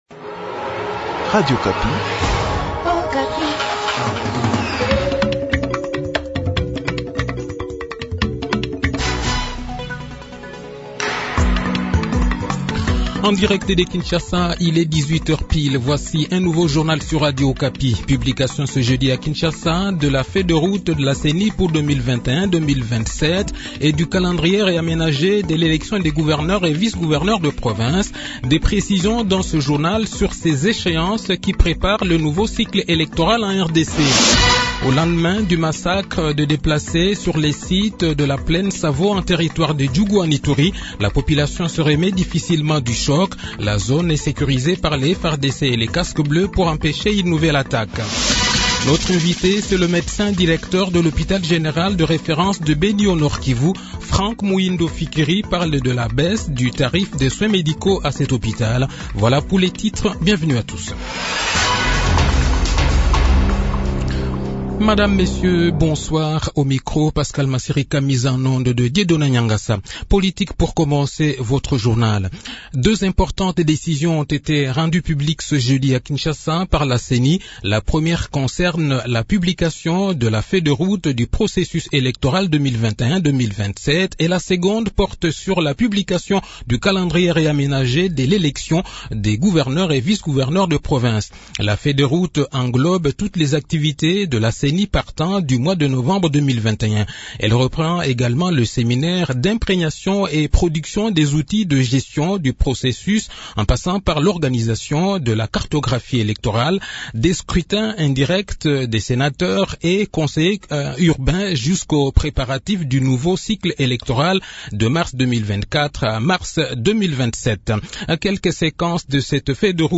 Le journal de 18 h, 3 février 2022